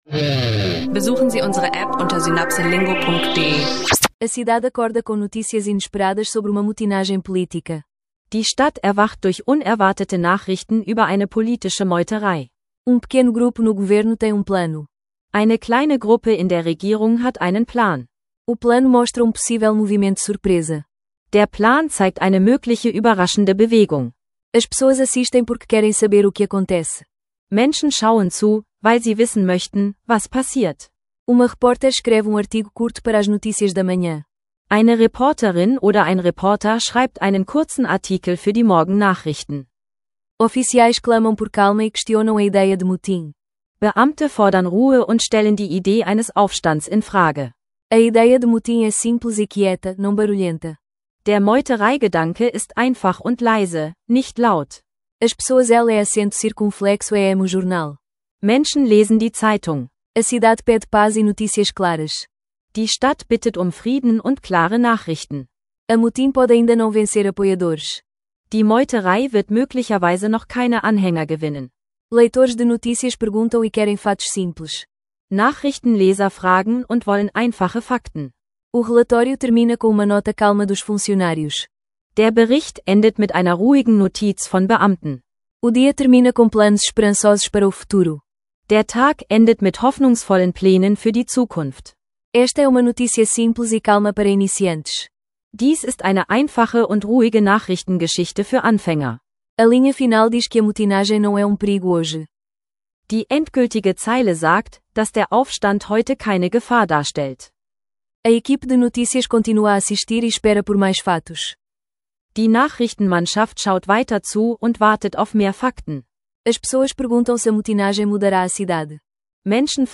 Eine ruhige, klare Geschichte für Anfänger: Portugiesisch lernen mit Alltagsthemen zu Bankwesen, Nachrichtenstil und Lotterien.